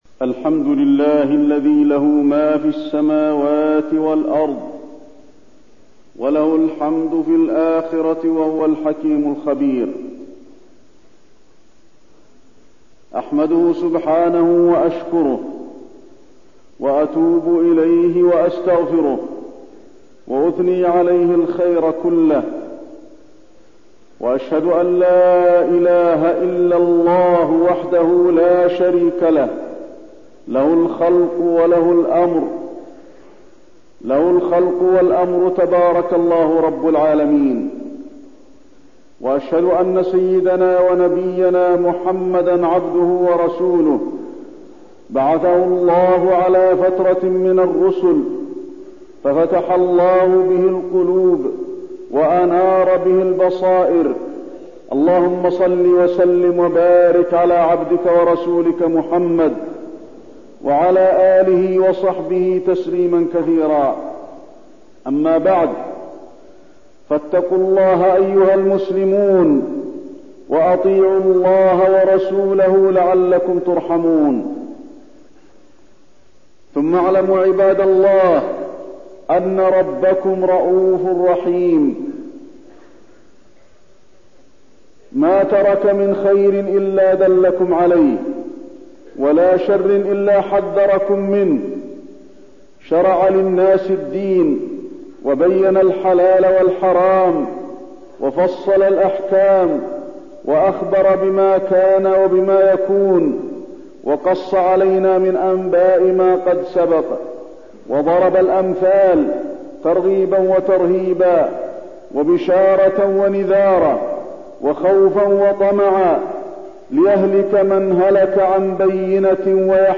تاريخ النشر ٤ رجب ١٤٠٩ هـ المكان: المسجد النبوي الشيخ: فضيلة الشيخ د. علي بن عبدالرحمن الحذيفي فضيلة الشيخ د. علي بن عبدالرحمن الحذيفي الحلال والحرام والمخدرات